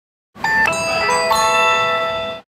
magic-band-sound-effect-clear-just-audio-walt-disney-world.mp3